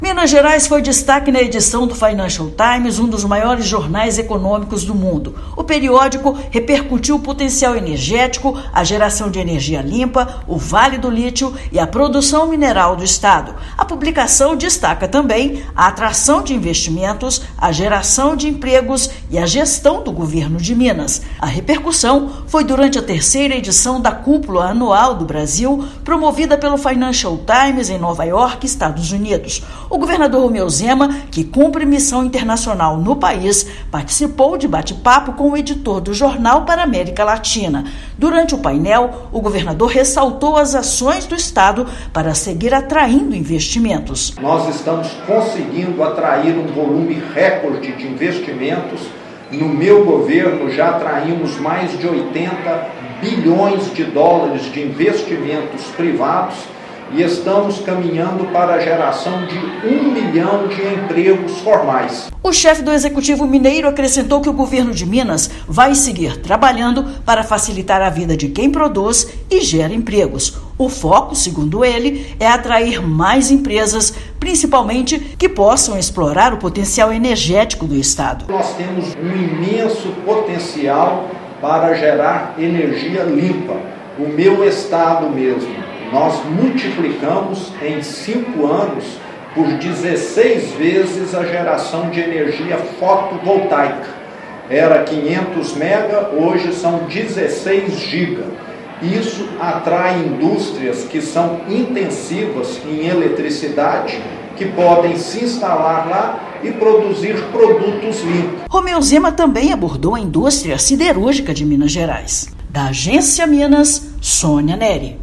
Periódico americano ressalta os potenciais energéticos de Minas, a geração de empregos e o desenvolvimento do estado. Ouça matéria de rádio.